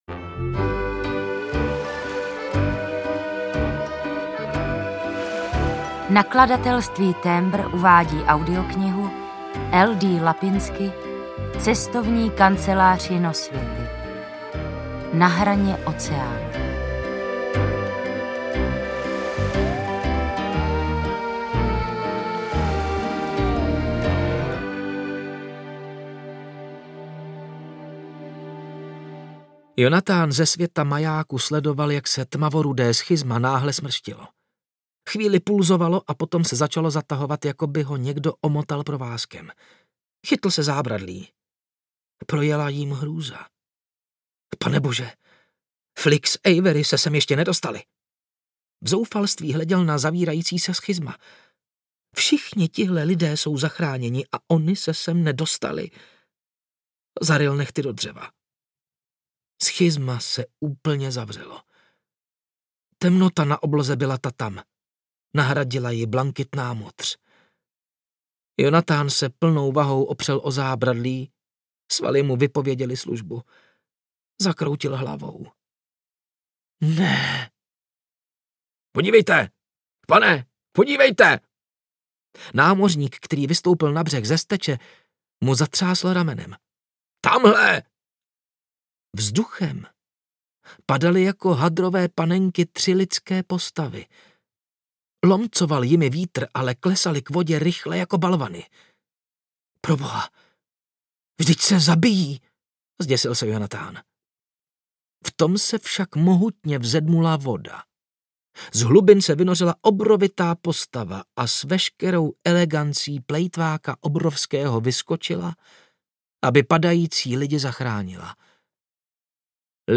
Na hraně oceánu audiokniha
Ukázka z knihy